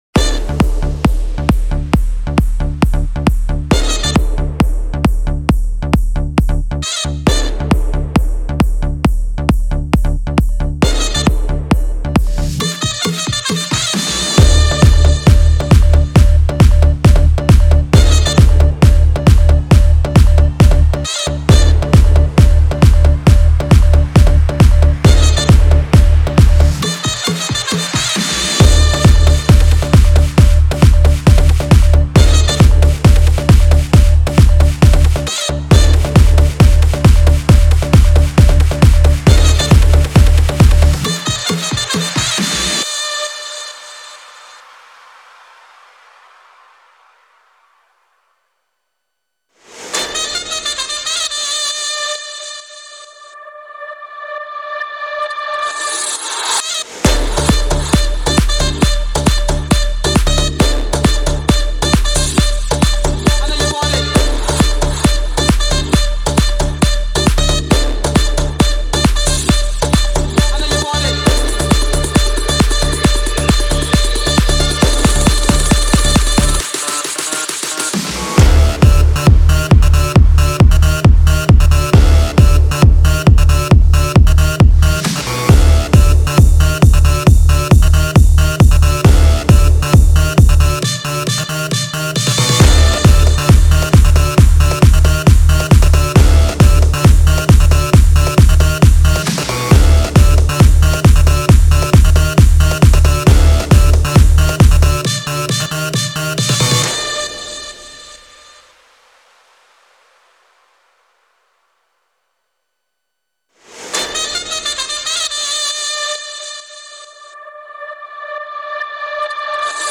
DJ SONG